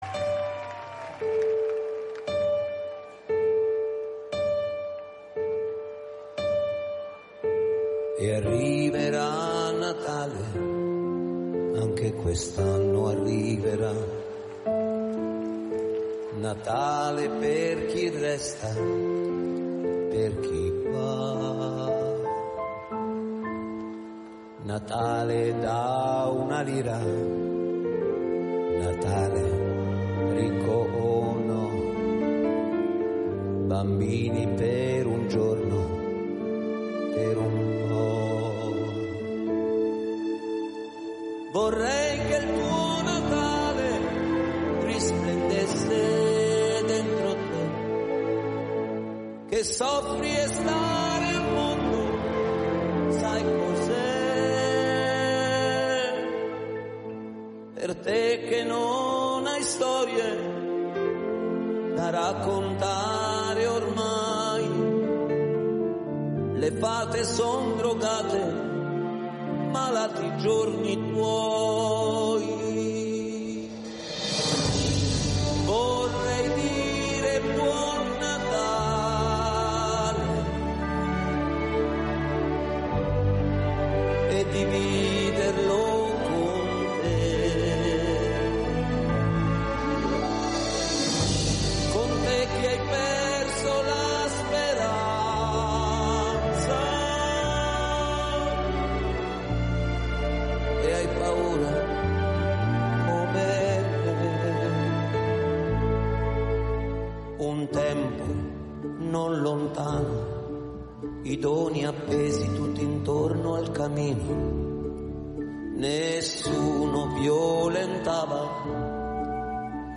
Voce solista
Fisarmonica solista